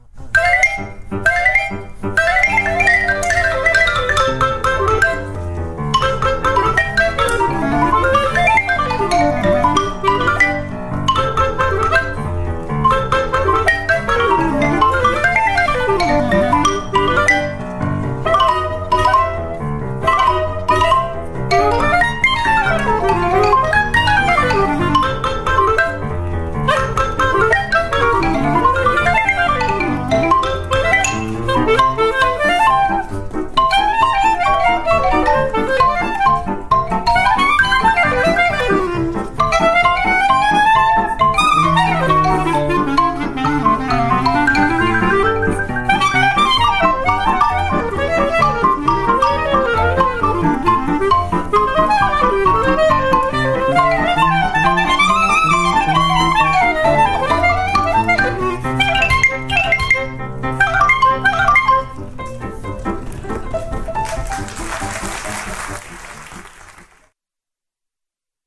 clarinette
piano
vibraphone
batterie